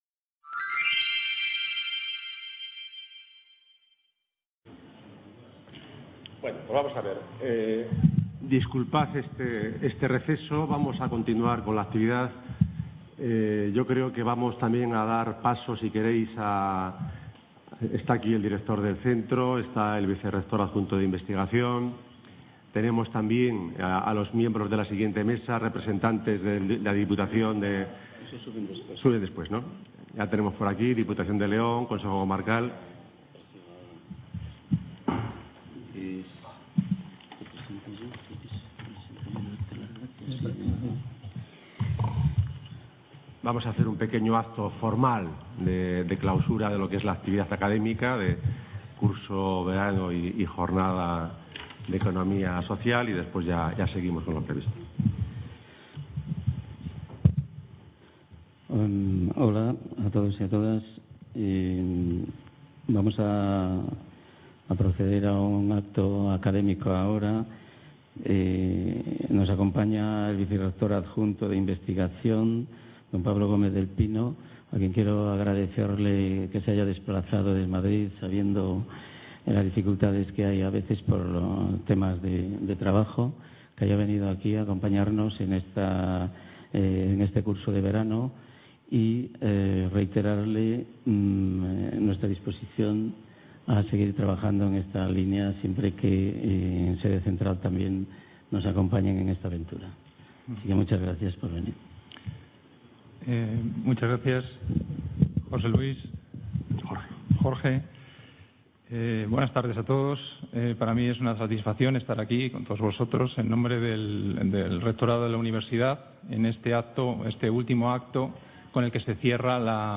Mesa redonda: El papel de las instituciones locales en… | Repositorio Digital